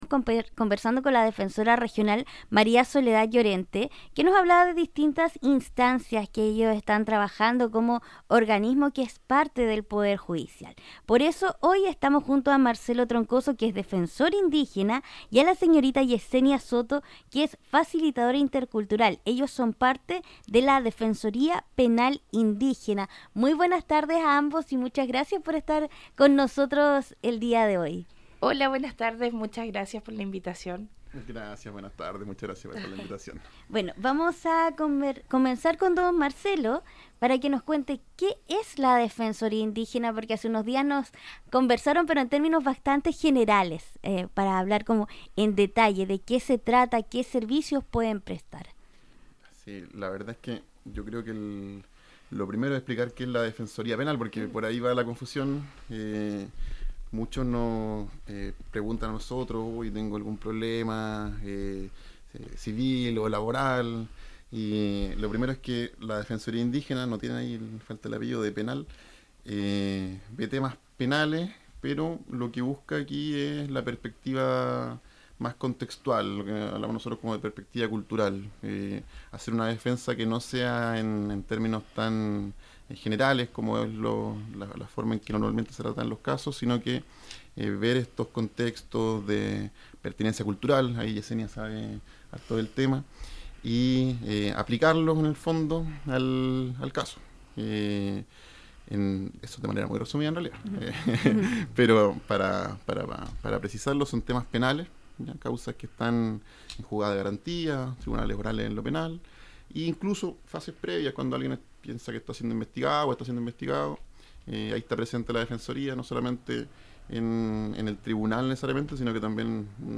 En entrevista radial, los profesionales detallaron su labor territorial, destacando la necesidad de comprender la cosmovisión de los pueblos originarios para garantizar un real acceso al sistema judicial.